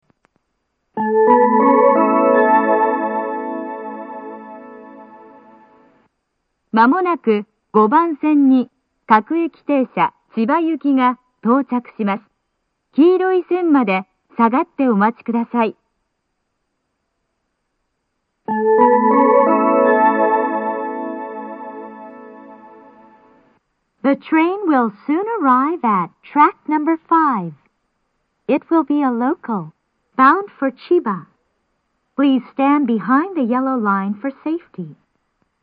この駅は禁煙放送が流れていて、それが被りやすいです。
５番線接近放送 各駅停車千葉行の放送です。